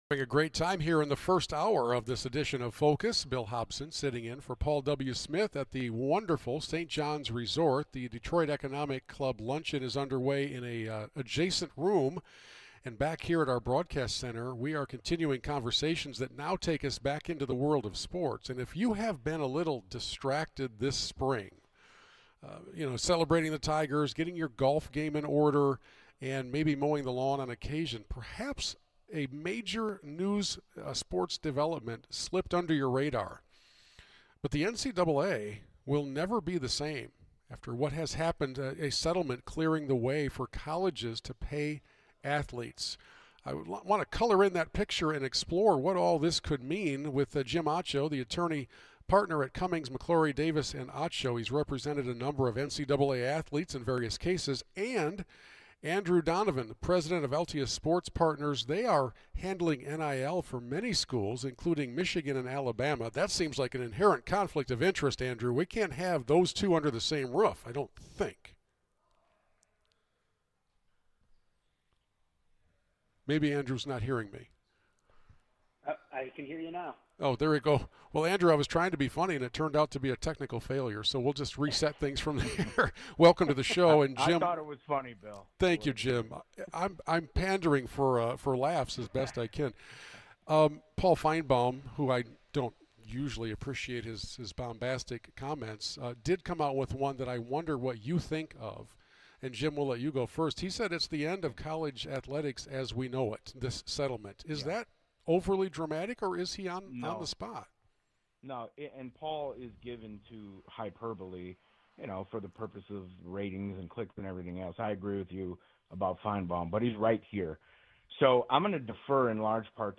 The interview is very brief/truncated due to time constraints, but a longer podcast version will follow at the end of the week; if you are interested, circle back and we will update this post with the longer podcast.